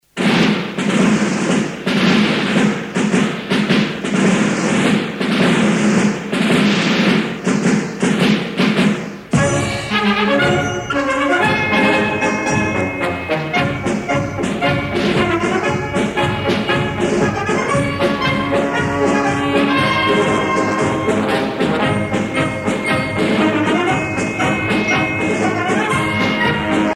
Fonction d'après l'analyste gestuel : à marcher